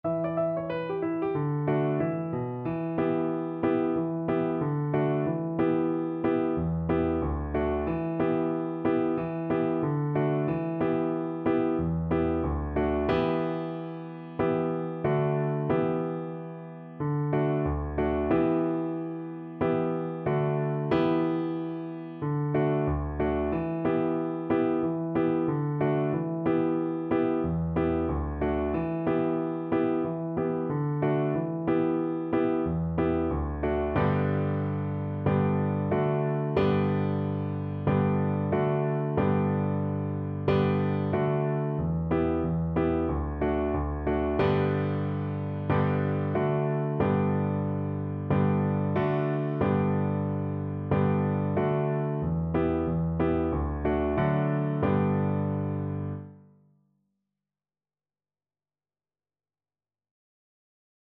Violin
B minor (Sounding Pitch) (View more B minor Music for Violin )
4/4 (View more 4/4 Music)
D5-E6
Traditional (View more Traditional Violin Music)
Reels